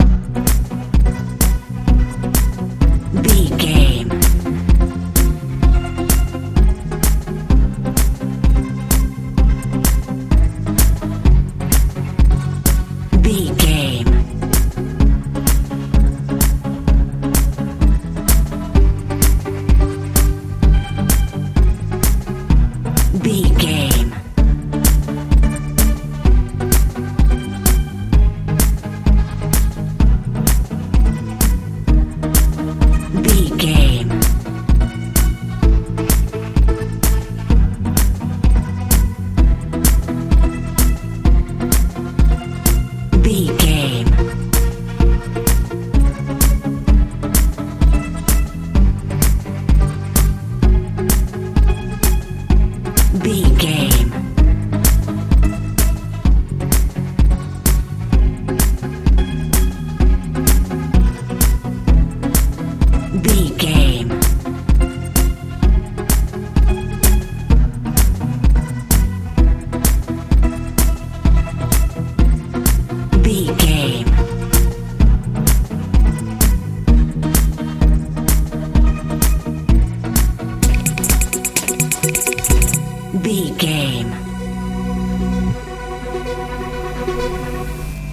modern dance feel
Ionian/Major
dreamy
sweet
synthesiser
bass guitar
drums
80s
90s